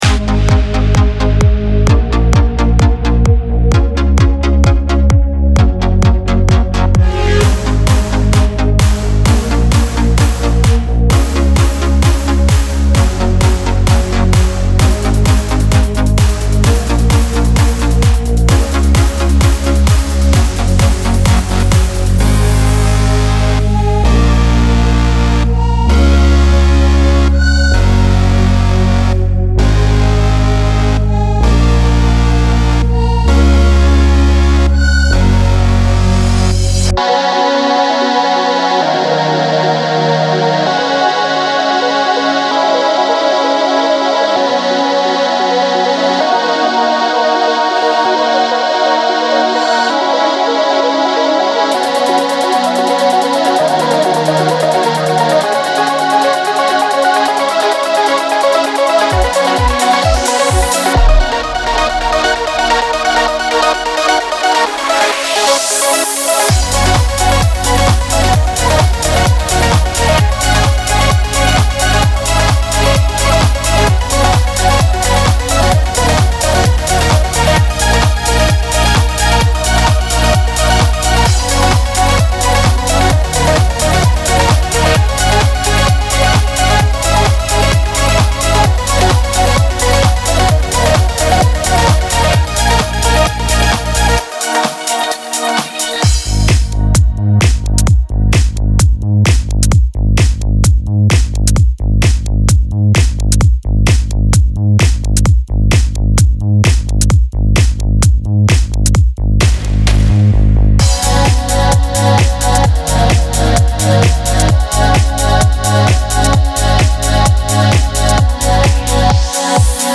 Покритикуйте объективно арранж/сведение Progressive House
Привет! Заранее прошу прощения, что трек неполный, без вокала.
Поэтому я решил пока догнать минус, там , где вокала быть не должно. Там где будет (1-я яма и после 1-й кульминации) - эти места оставил пока как есть.